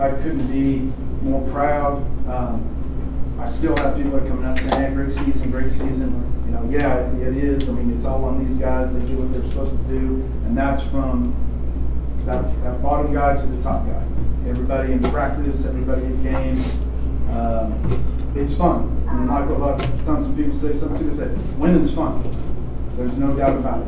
The Dewey Bulldoggers boys basketball team held an end-of-season banquet on Sunday evening.